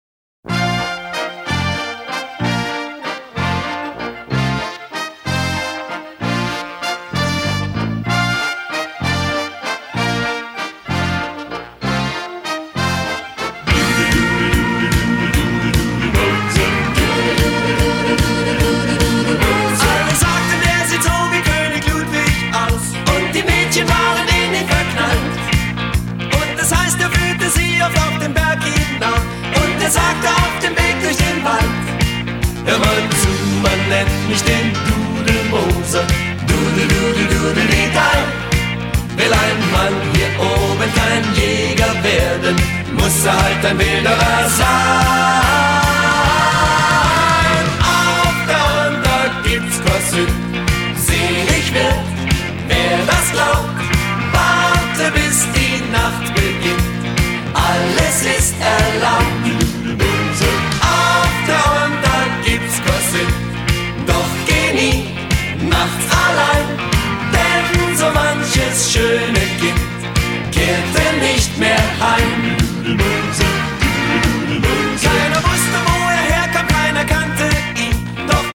Disco-Pop